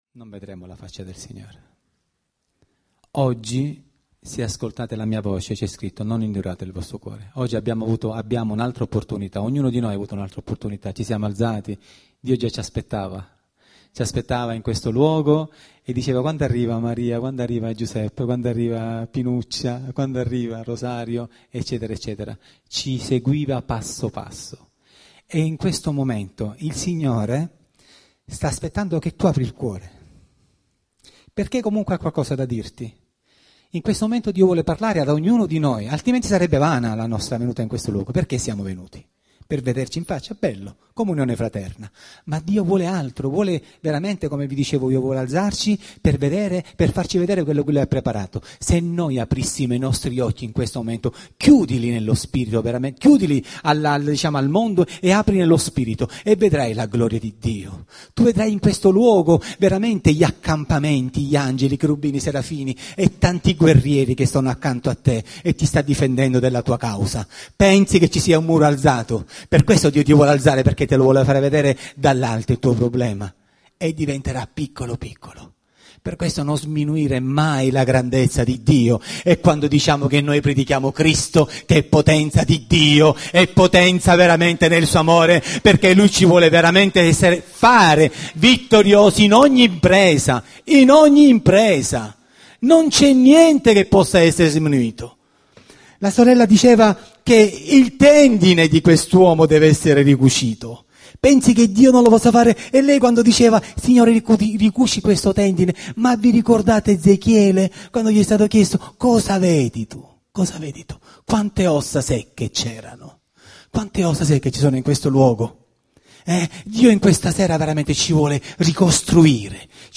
Predicazione
Questa domenica è stata una giornata particolare, in quanto nella nostra chiesa abbiamo avuto la visita dei fratelli di Compassion.